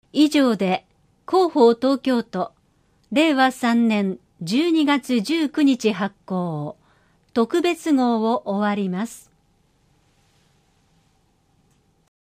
「広報東京都音声版」は、視覚に障害のある方を対象に「広報東京都」の記事を再編集し、音声にしたものです。
終わりアナウンス（MP3：96KB） 12秒